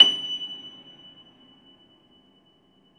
53c-pno24-F5.wav